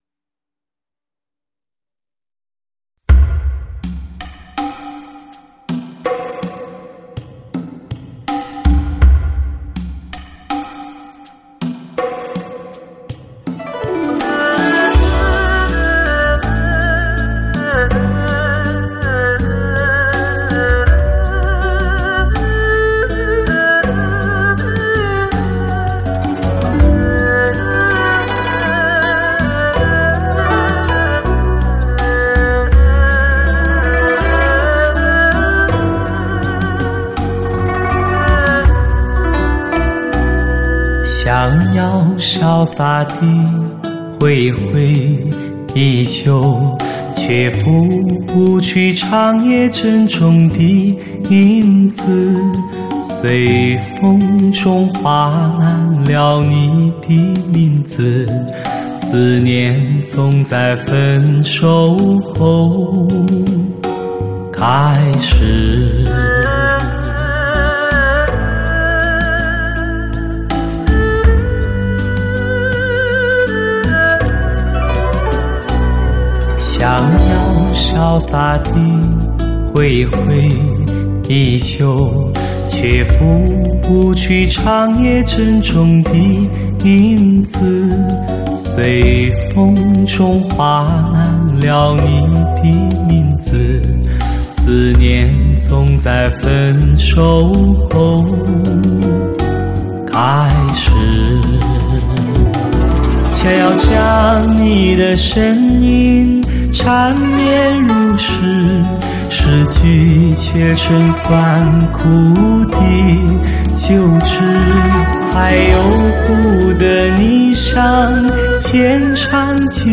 全方位多位环绕
发烧老情歌 纯音乐
极致发烧HI-FI人声测试碟